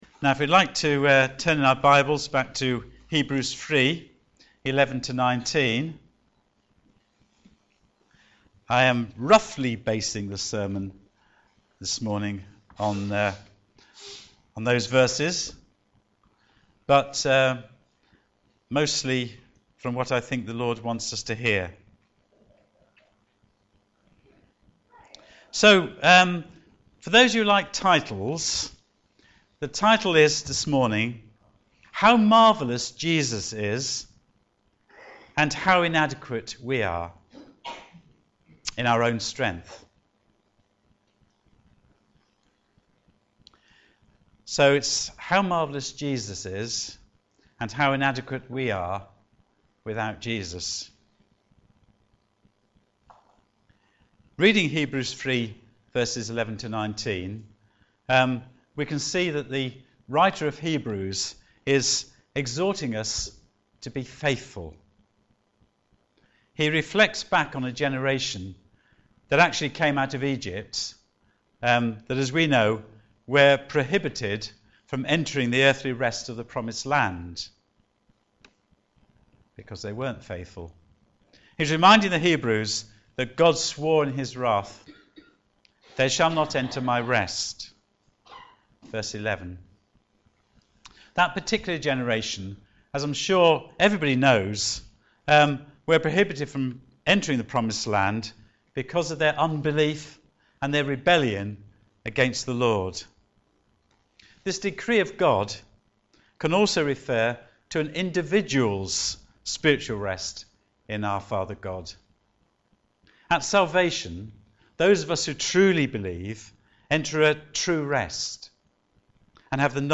Media for a.m. Service on Sun 25th Oct 2015 10:30
Series: Christ Above All Theme: How marvellous Jesus is Sermon